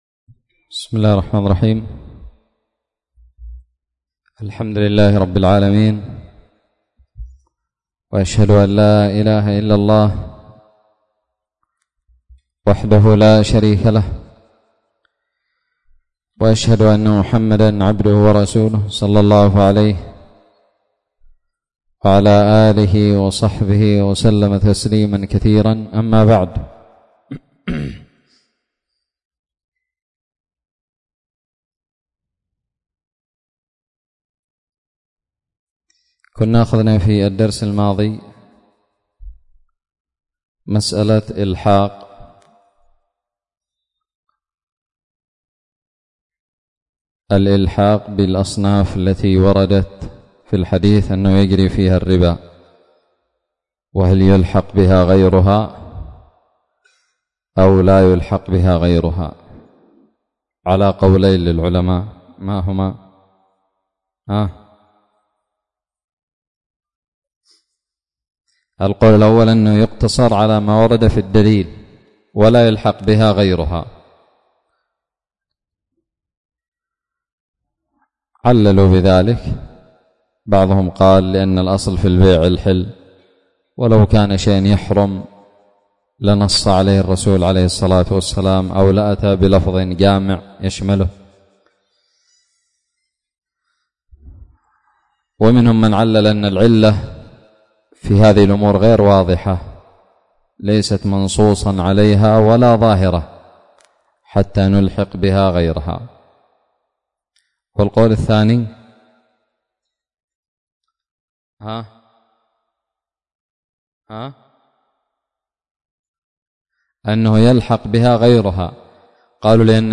ألقيت بدار الحديث السلفية للعلوم الشرعية بالضالع.